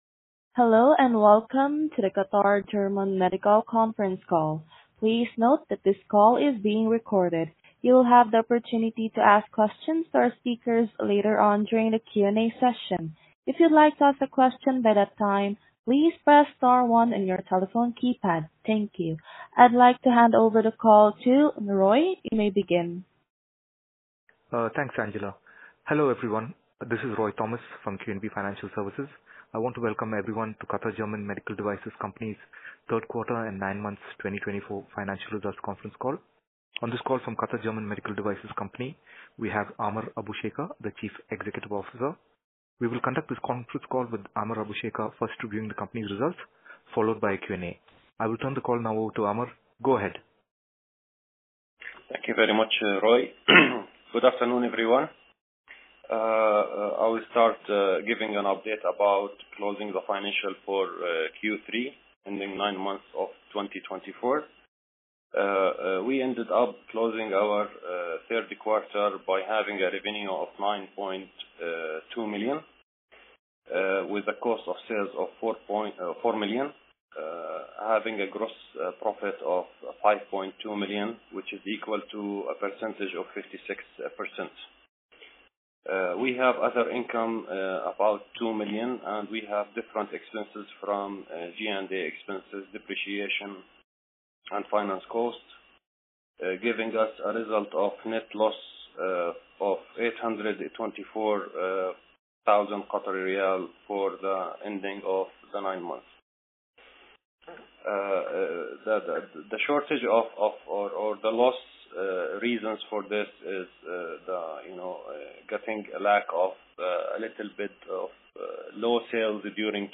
Conference Call